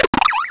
pokeemerald / sound / direct_sound_samples / cries / tranquill.aif
tranquill.aif